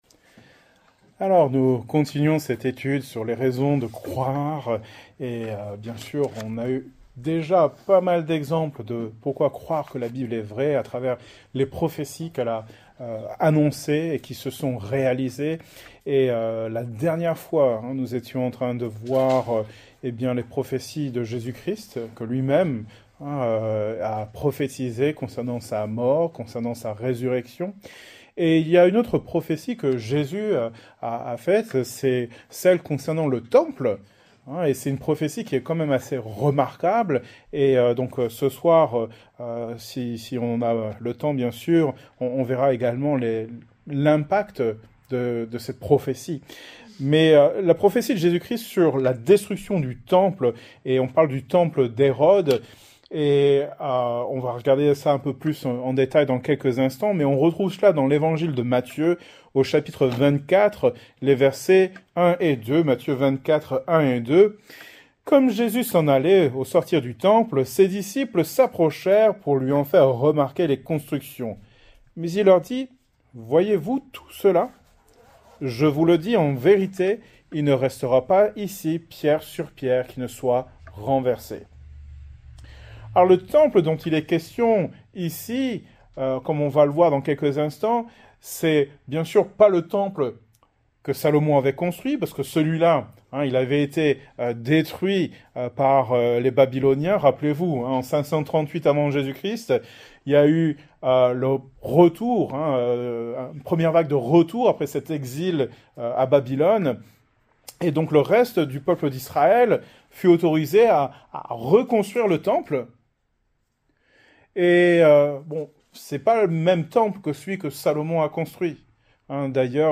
Thème: Apologétique , Foi Genre: Etude Biblique